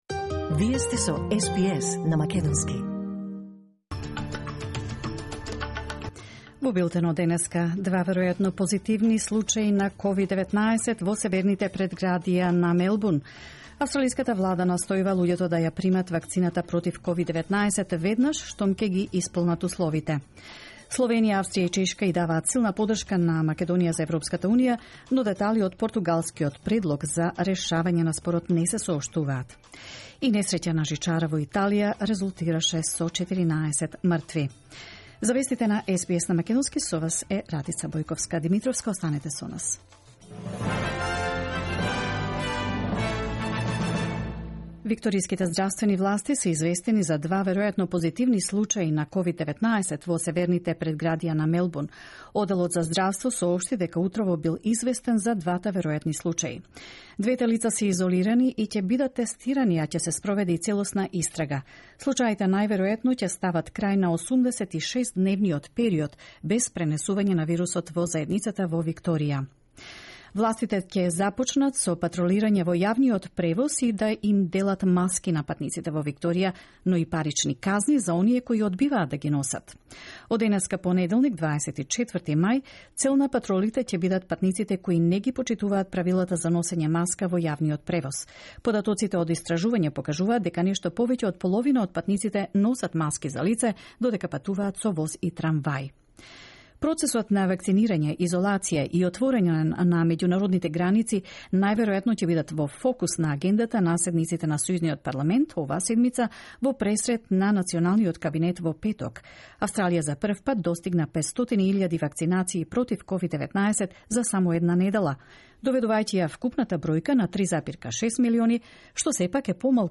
Вести на СБС на македонски 24 мај 2021
SBS News in Macedonian 24 May 2021